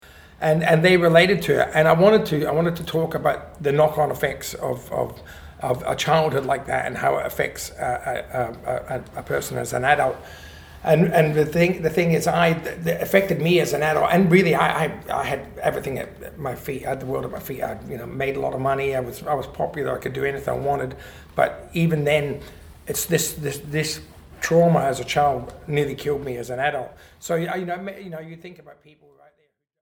Our podcasts are recorded in our makeshift studio here at Booktopia. We use a single mic in a booth.
Listen to Aussie rock legend Jimmy Barnes on the Booktopia Podcast chatting about his memoir Working Class Man (HarperCollins)—the sequel to his #1 bestseller Working Class Boy (HarperCollins).